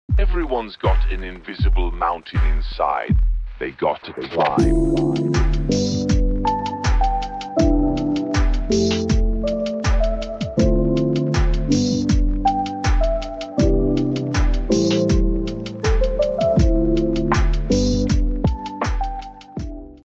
Lo-Fi Chillhop Type Beats